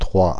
Ääntäminen
Ääntäminen Paris: IPA: [tʁwa] Tuntematon aksentti: IPA: /trwa/ Haettu sana löytyi näillä lähdekielillä: ranska Käännöksiä ei löytynyt valitulle kohdekielelle.